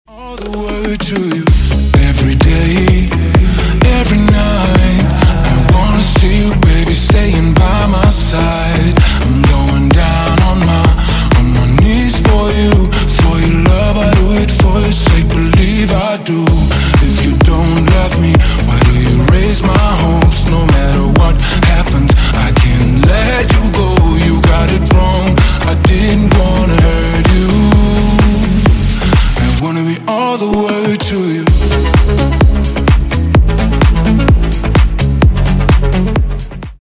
# Клубные Рингтоны
# Танцевальные Рингтоны